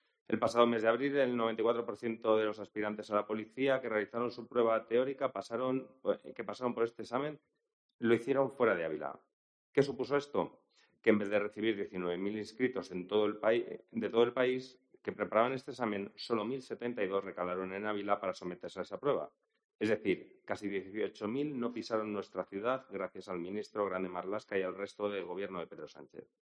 Jorge Pato, portavoz del PP en el Ayuntamiento. Moción pruebas Escuela Policía